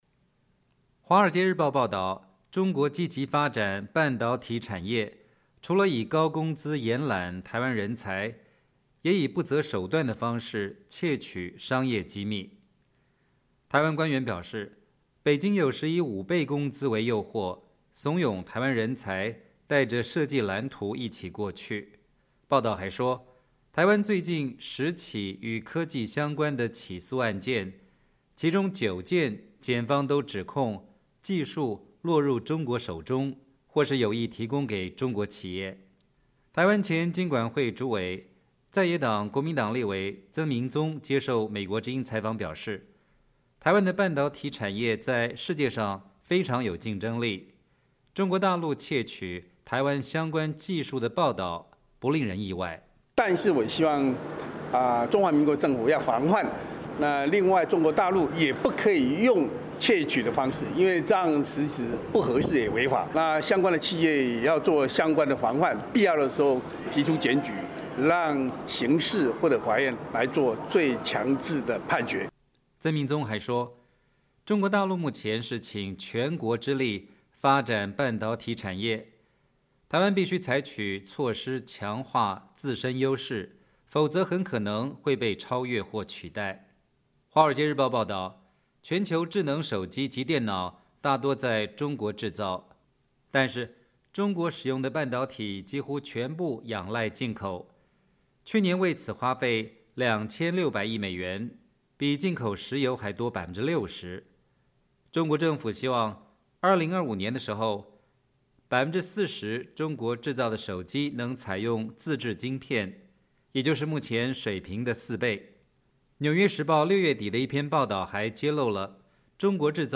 台湾前金管会主委、在野党国民党立委曾铭宗接受美国之音采访表示，台湾的半导体产业在世界上非常有竞争力，中国大陆窃取台湾相关技术的报道不令人意外。
台湾执政党民进党立委黄伟哲告诉美国之音，连美国企业都饱受商业间谍之苦，台湾的企业实在也很难幸免。